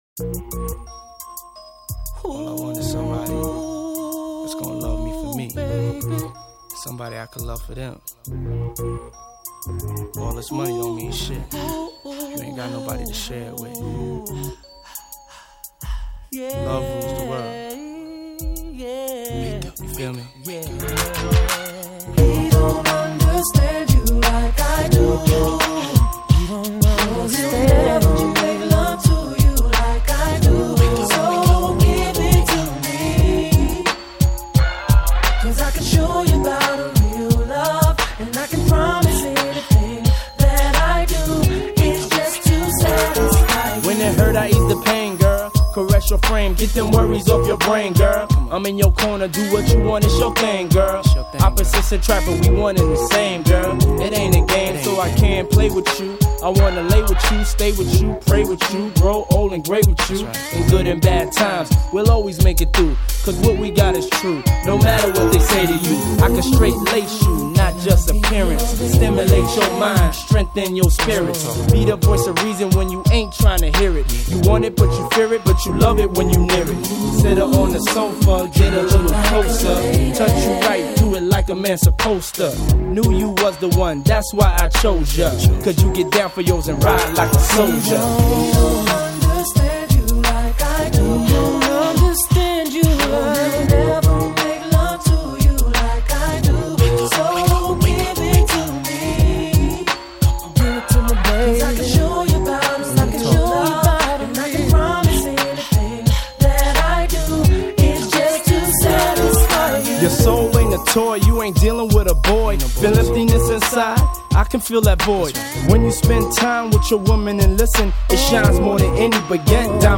Жанр: Rap
Рэп Хип-хоп.